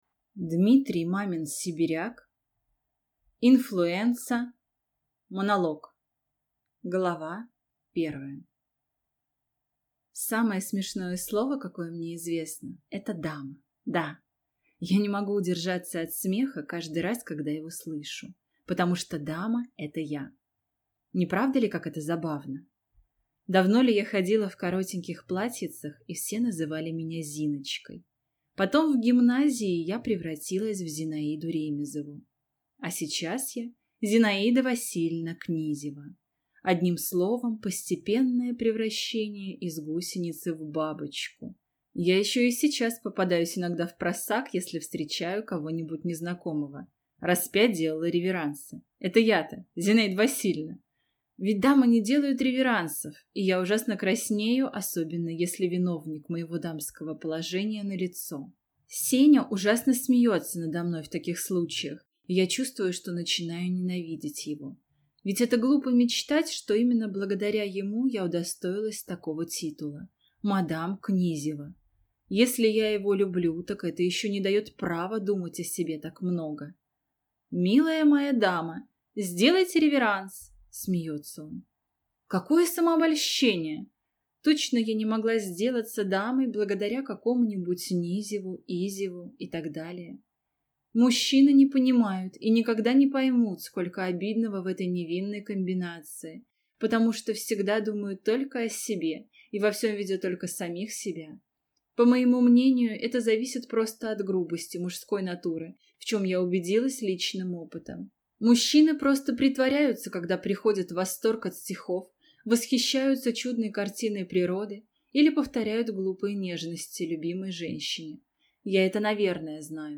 Аудиокнига Инфлуэнца | Библиотека аудиокниг
Прослушать и бесплатно скачать фрагмент аудиокниги